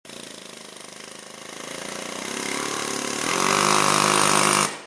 Perforator.mp3